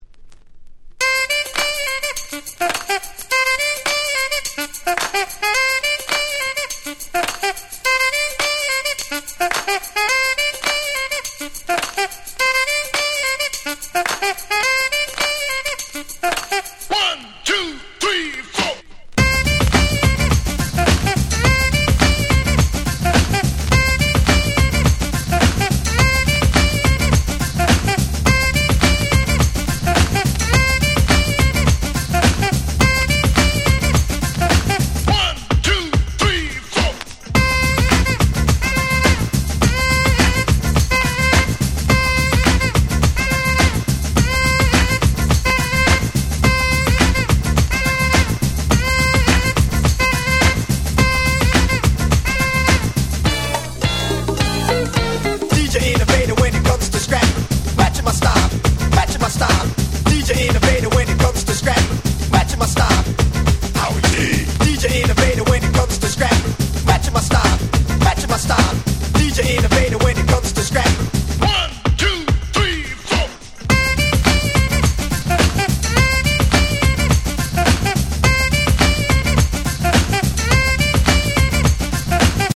91' Super Hit Jazzy Break !!
R&B